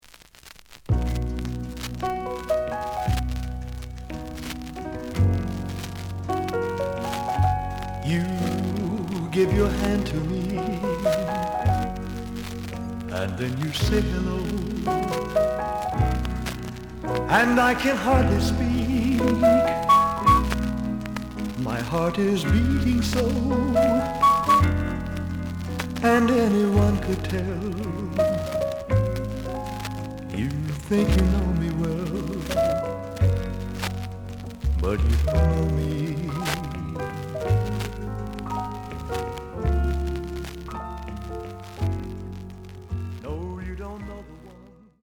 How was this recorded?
The audio sample is recorded from the actual item. Some noise on B side.